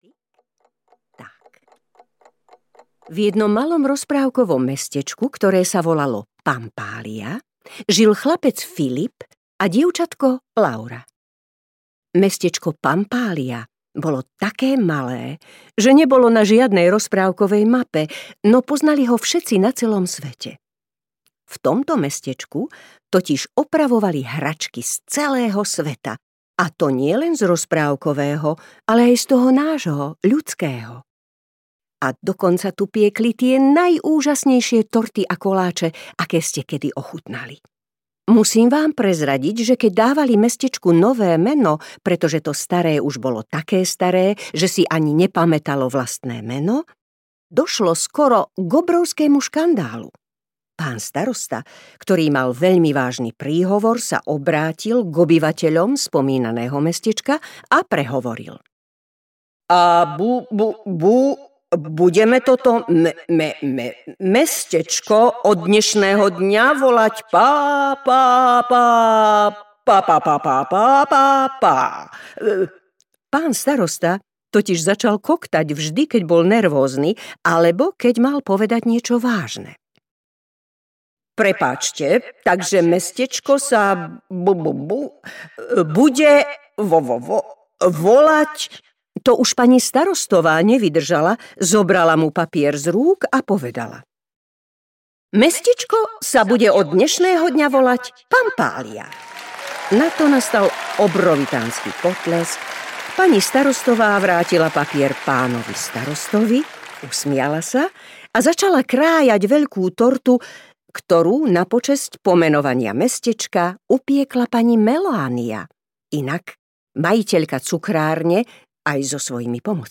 Pampália audiokniha
Ukázka z knihy